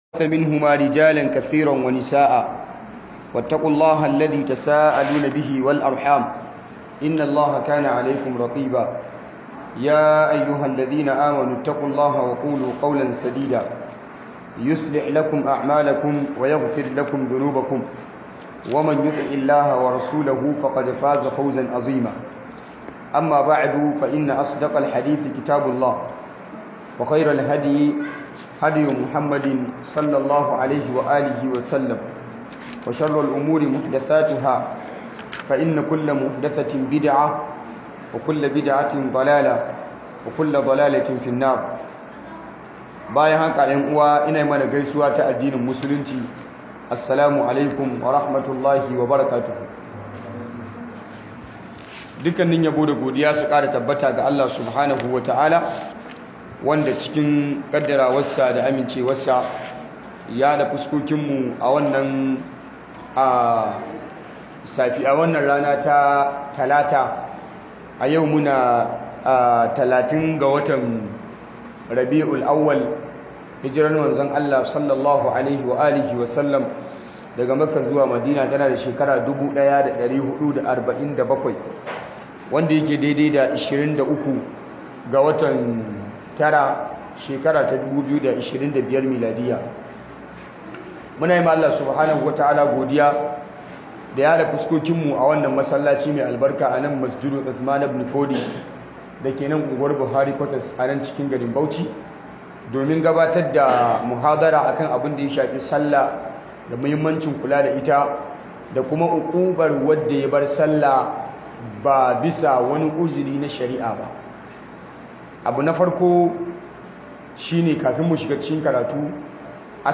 Muhadara Akan Sallah